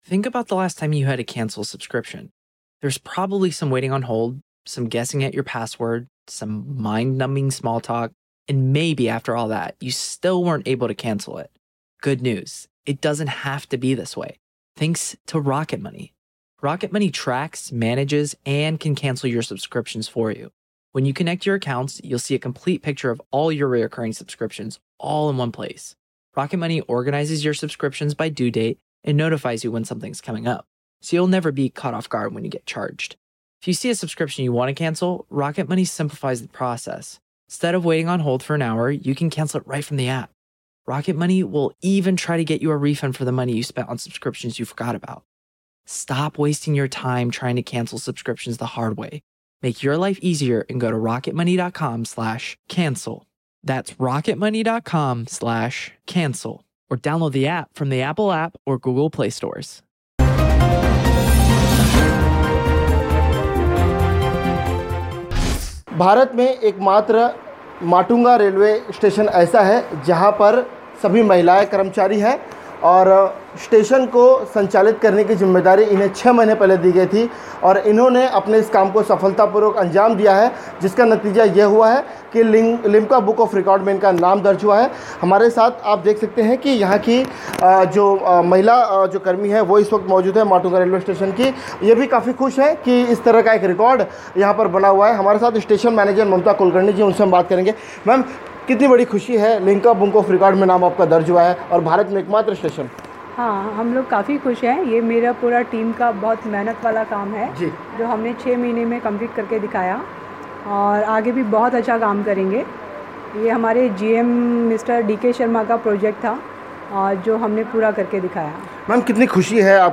News Report / लेडीज स्पेशल' माटुंगा रेलवे स्टेशन का नाम लिम्का बुक ऑफ रेकॉर्ड्स 2018 में दर्ज